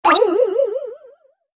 Converted sound effects